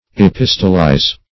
Epistolize \E*pis"to*lize\, v. i. To write epistles.